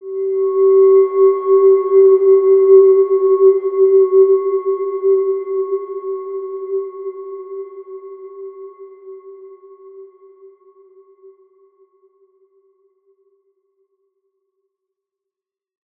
Evolution-G4-mf.wav